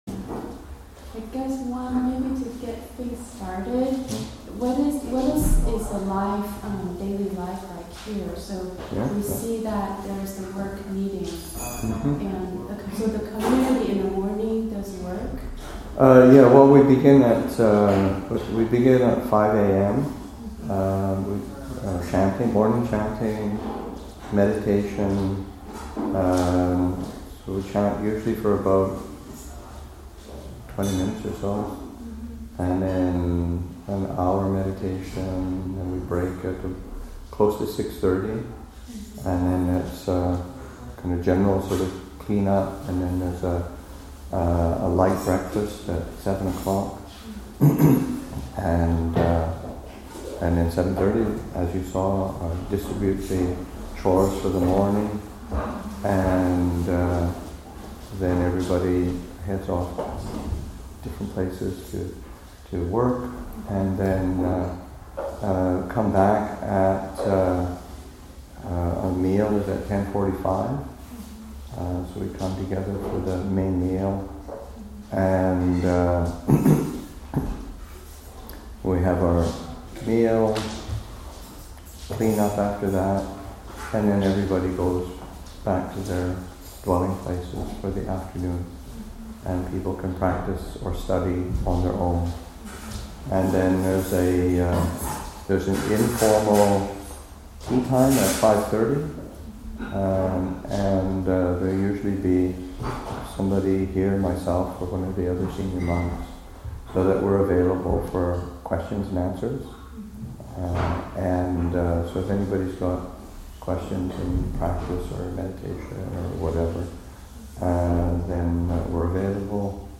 Q&A session, Nov. 18, 2014